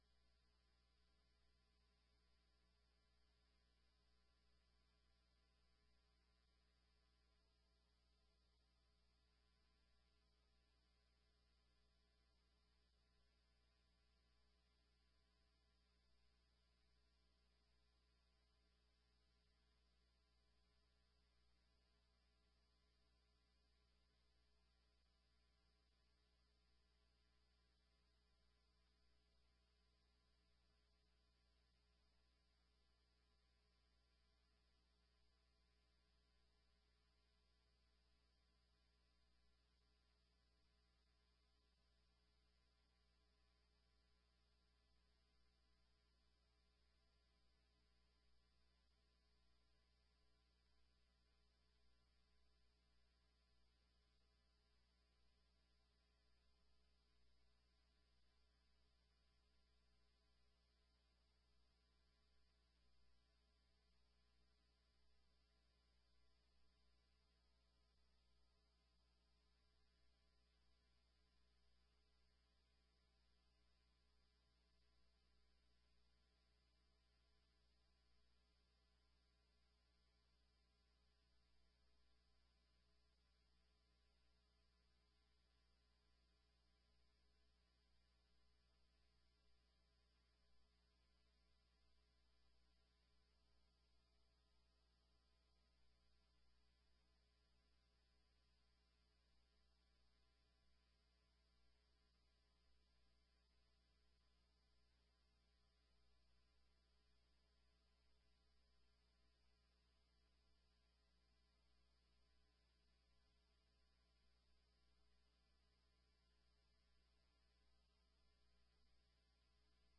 7ª Sessão Ordinária de 2017